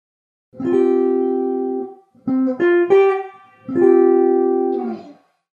E' possibile osservare qualsiasi insieme di note riferendosi all'intervallo che c'è tra le note stesse: ad esempio, se abbiamo un accordo formato dalle lettere C, F e G, [